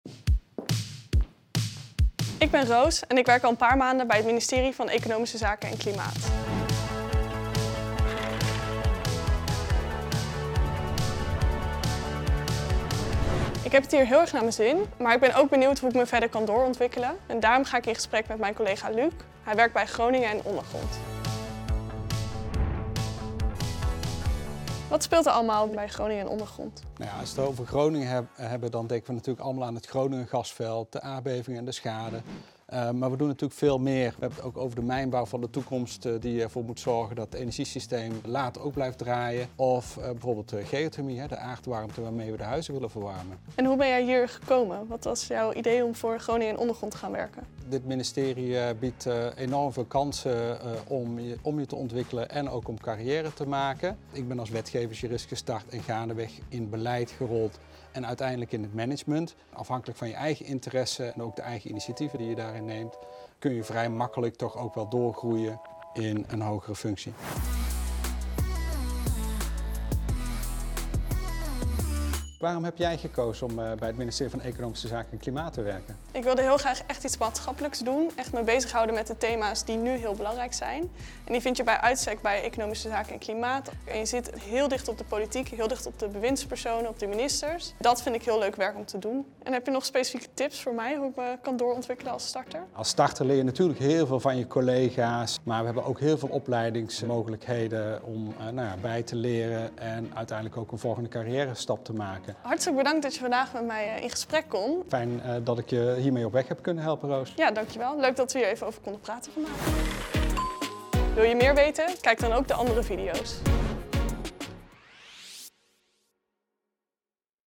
In de videoserie Op Je Plek Bij gaan starters in gesprek met ervaren collega’s over het werken en de loopbaanmogelijkheden bij het ministerie van Economische Zaken (EZ, voorheen het ministerie van Economische Zaken en Klimaat).